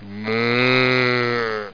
Moo.mp3